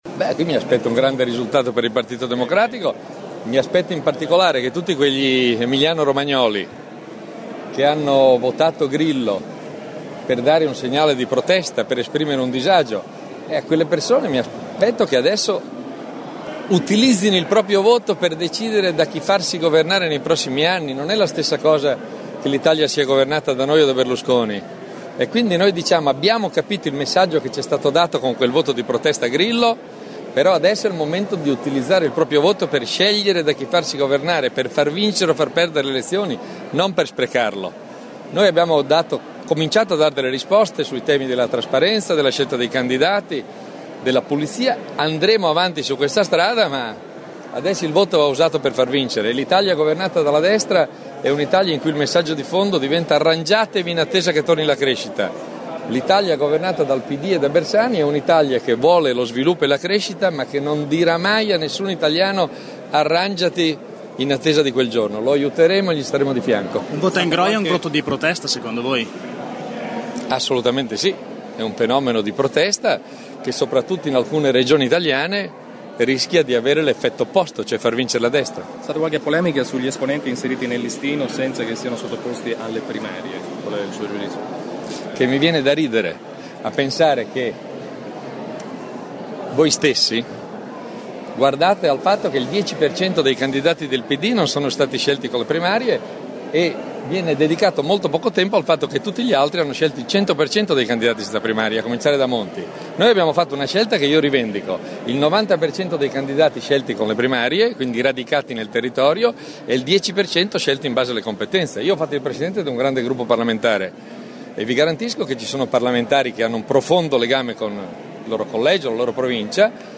Questi gli ingredienti della presentazione di questa mattina dei candidati nelle liste emiliano romagnole del Partito Democratico alle prossime elezioni politiche. In Cappella Farnese, a palazzo d’Accursio, affianco al segretario regionale del partito Stefano Bonaccini, c’erano i capolisti di Camera, Dario Franceschini, e Senato, Josefa Idem.